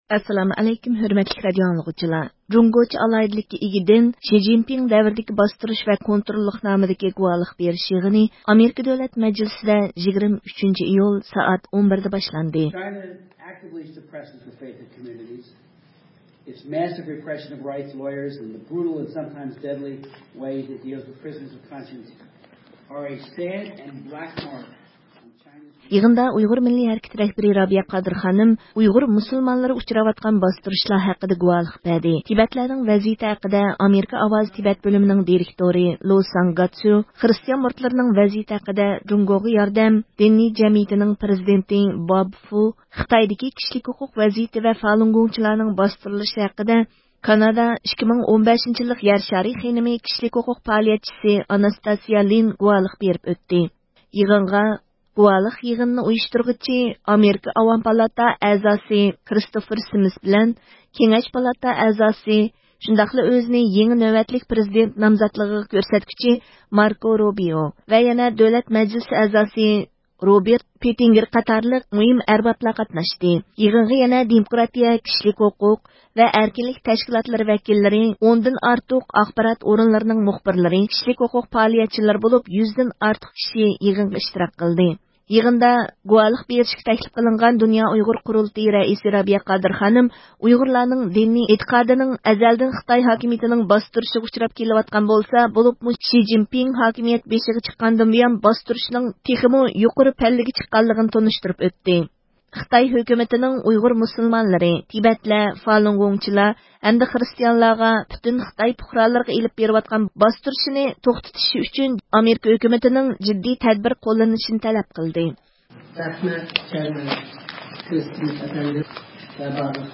رابىيە خانىم ئامېرىكا دۆلەت مەجلىسىدە خىتاينىڭ دىنىي باستۇرۇشلىرى ھەققىدە گۇۋاھلىقتىن ئۆتتى – ئۇيغۇر مىللى ھەركىتى
«جۇڭگوچە ئالاھىدىلىككە ئىگە دىن: شى جىنپىڭ دەۋردىكى باستۇرۇش ۋە كونتروللۇق» نامىدىكى گۇۋاھلىق بېرىش يىغىنى ئامېرىكا دۆلەت مەجلىسىدە 23 – ئىيۇل سائەت 11 دە باشلاندى.